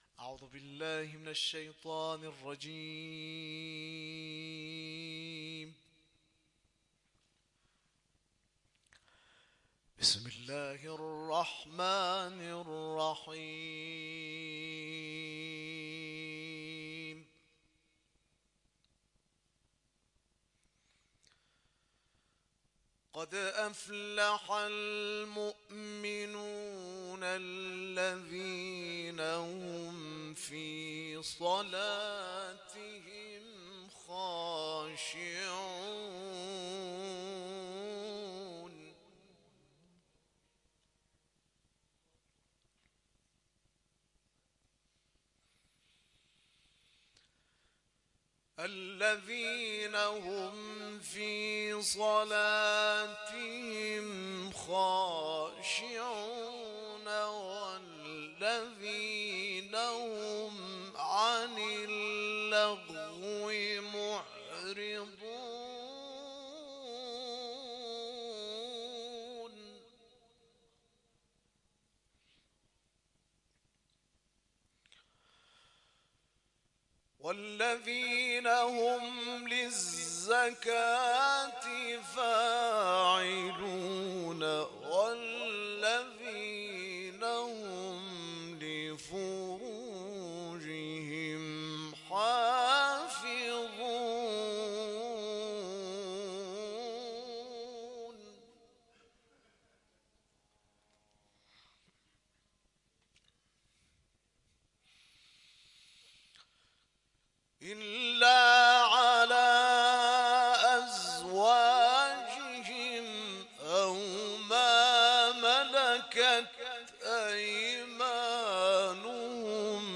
تلاوت
گفتنی است؛ این مراسم روز گذشته بعد از نماز عشاء، در کیانپارس، خیابان یکم شرقی، حسینه امام رضا(ع) برپا شد.